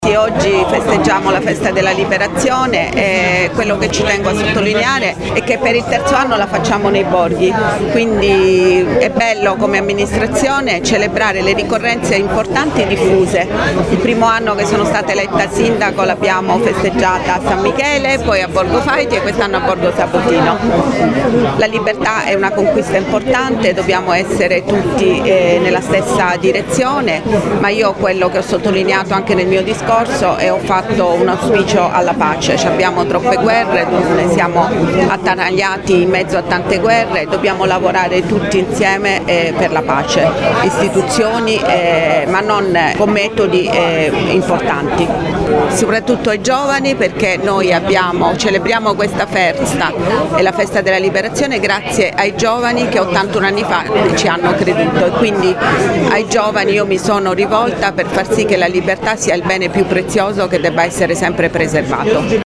Si sono tenute questa mattina, presso il Monumento ai Caduti di Borgo Sabotino, a Latina, le celebrazioni per il 25 Aprile, Festa della Liberazione d’Italia di cui quest’anno ricorre l’81esimo anniversario.
A seguire le parole della Sindaca di Latina Matilde Celentano, e della Prefetta Vittoria Ciaramella.